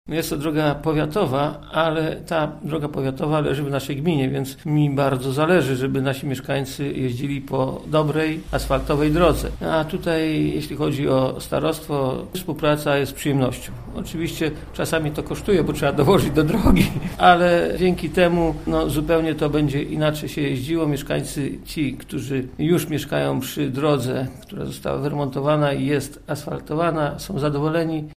– Ale ponieważ droga biegnie przez centrum Bobrowic, a powiat nie miał tyle pieniędzy, by ją wyremontować, postanowiliśmy zrobić to wspólnie – mówi Marek Babul, wójt gminy Bobrowice: